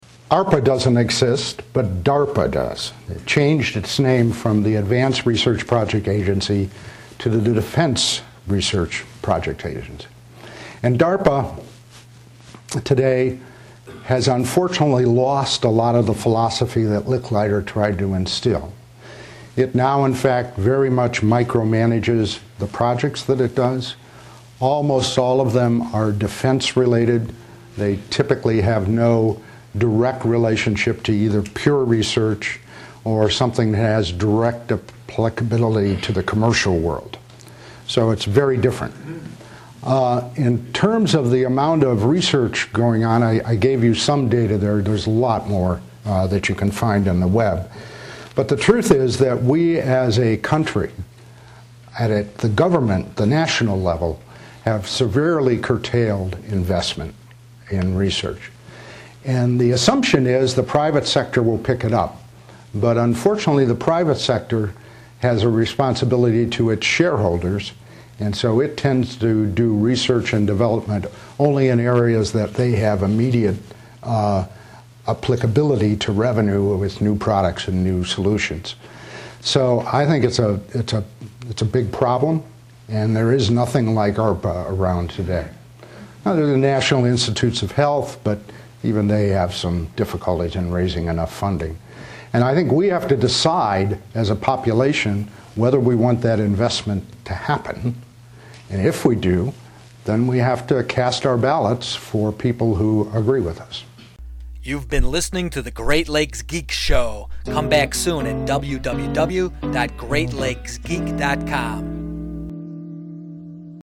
Here are some audio snippets of Geschke's answers to audience questions.